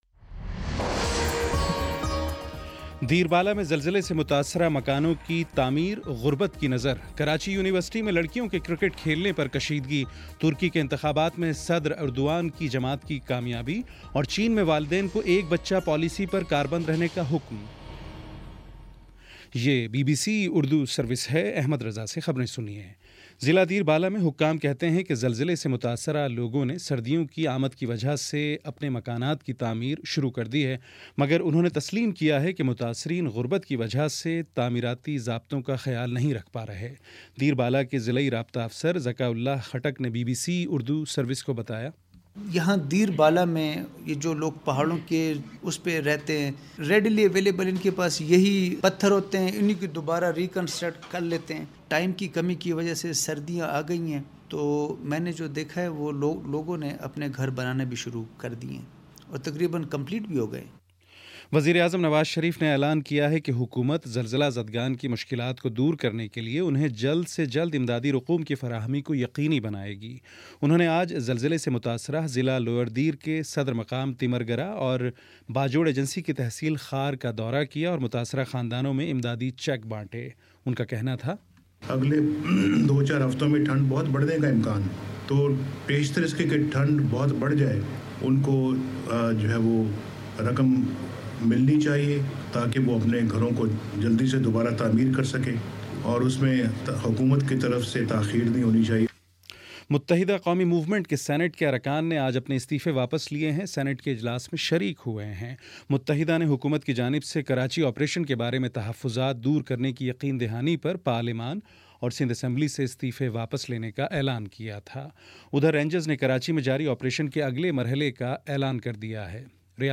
نومبر 02 : شام چھ بجے کا نیوز بُلیٹن